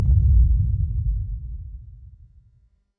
Cinematic Boom
A massive low-frequency cinematic boom impact with sub-bass rumble and slow decay
cinematic-boom.mp3